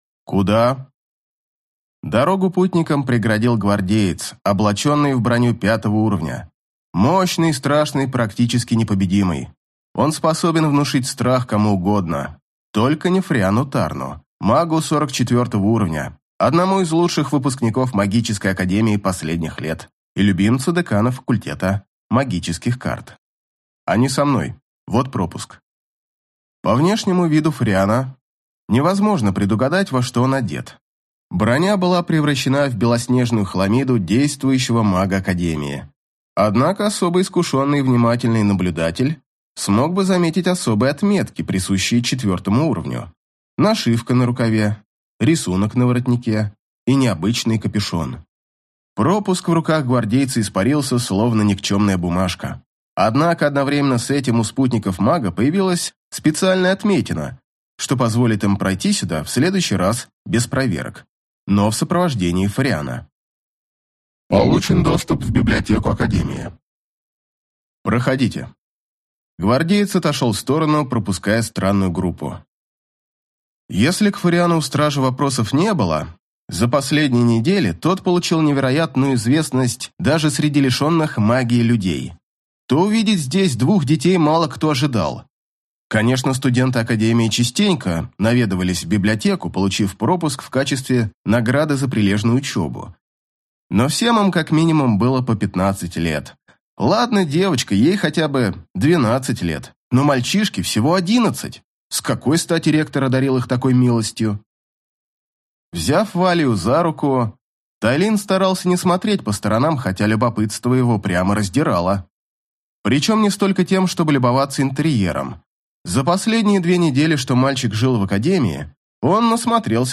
Аудиокнига Алхимик. Слеза Альрона | Библиотека аудиокниг